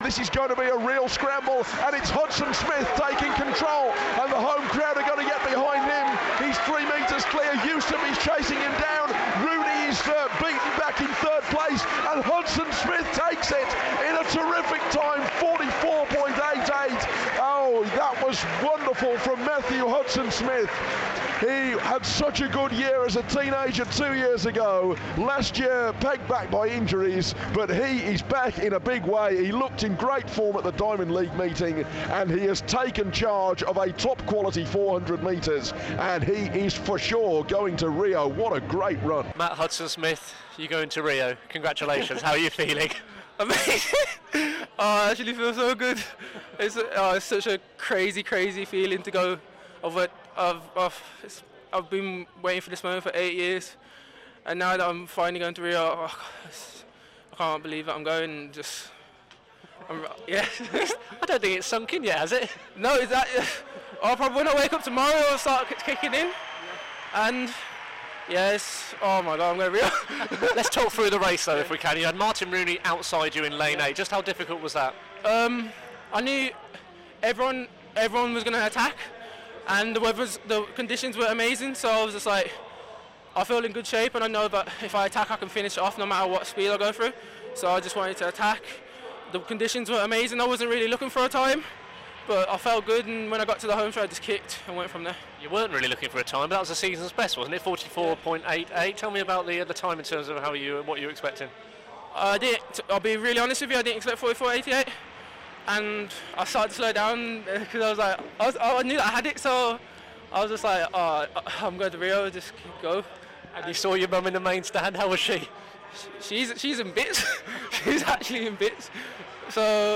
Wolverhampton 400m runner Matthew Hudson-Smith is overwhelmed with excitement talking to BBC WM after a stunning win at the British Championships which clinched his place in the Rio Olympics.